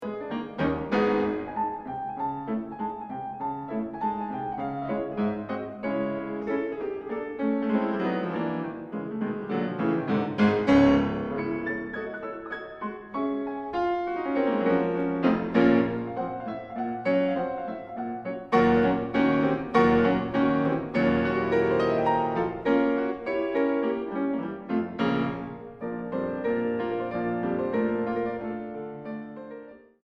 Tres Rapide ey Rythmé 1:44